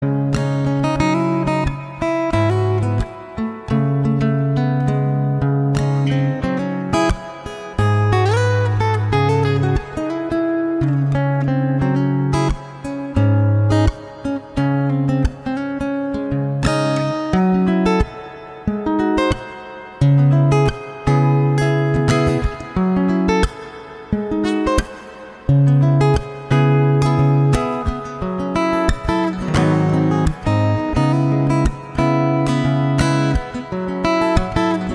Guitar Ringtones Instrumental Ringtones